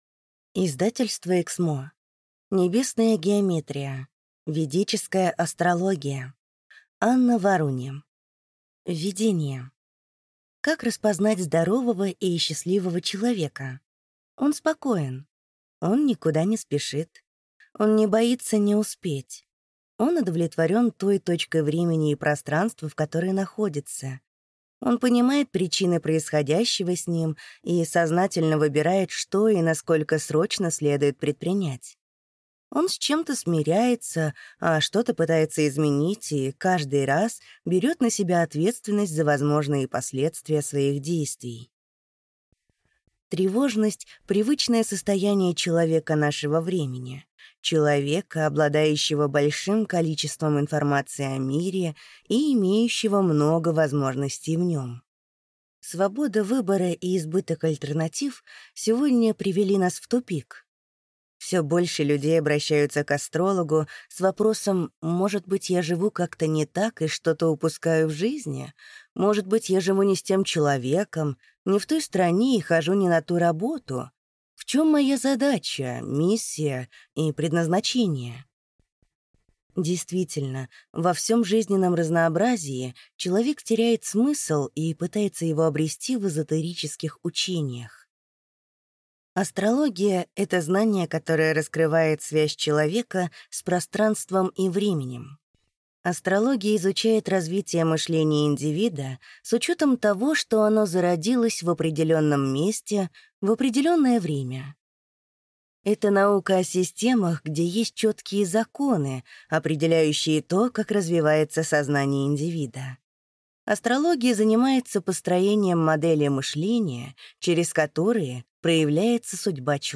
Аудиокнига Небесная геометрия. Ведическая астрология | Библиотека аудиокниг